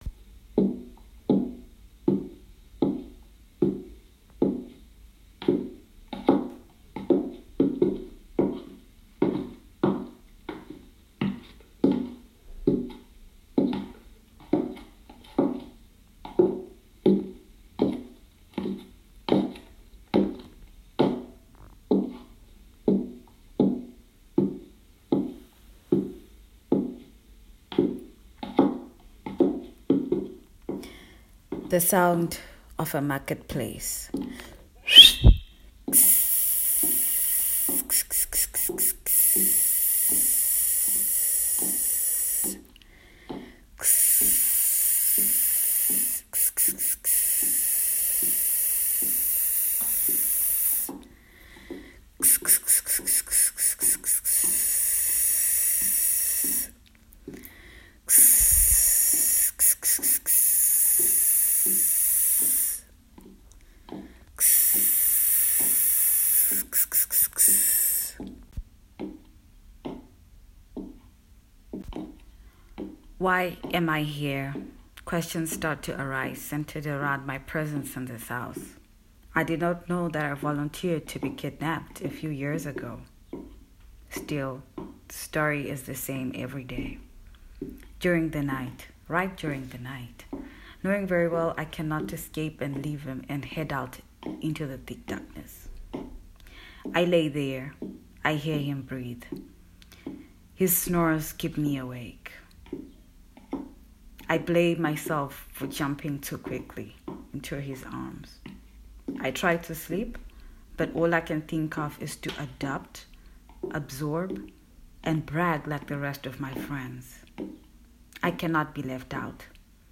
2021, performance and sound installation, durational piece
I thought it would get better... is a general re-enactment of events and a collection of memories, redefining the narrative through performative and familiar descriptive sounds in my indigenous household.